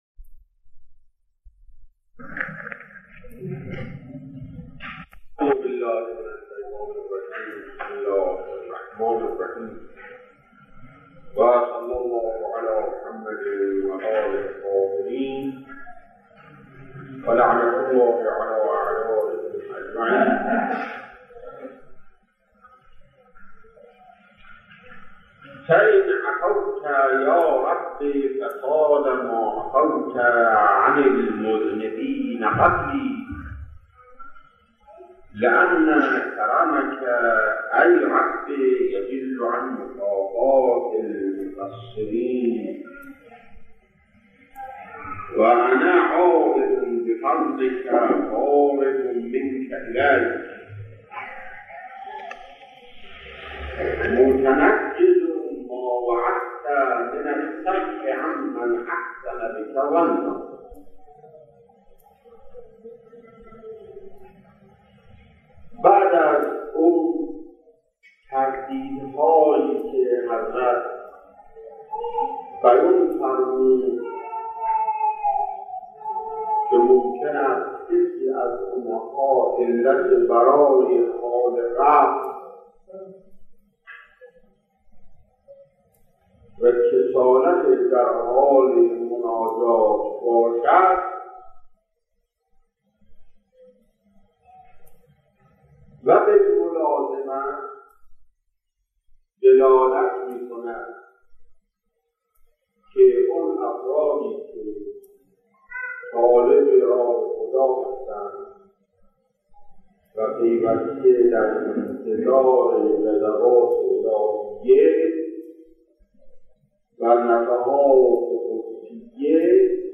سخنران علامه آیت‌اللَه سید محمدحسین حسینی طهرانی